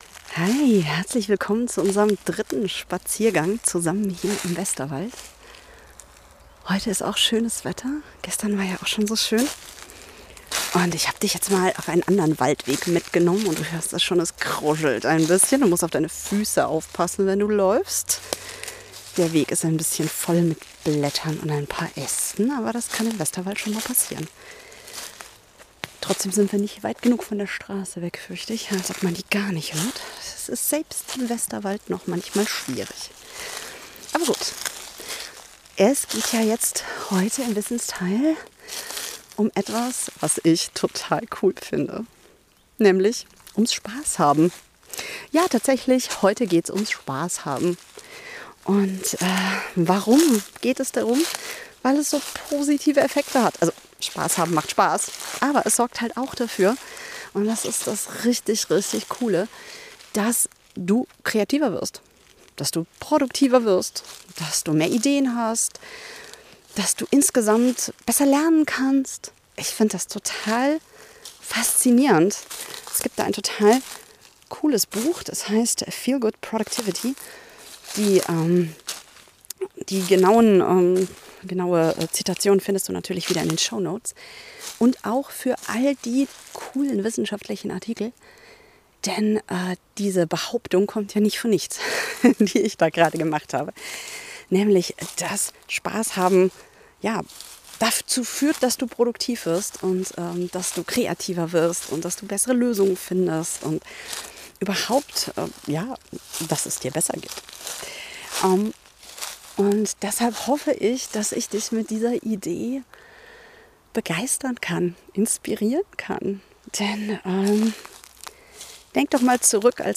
Ich nehme dich heute mit auf einen neuen Waldweg im
Es kruschelt unter den Füßen, eine Wespe begeistert
sich für mein Mikro und ein Hase läuft uns über den Weg.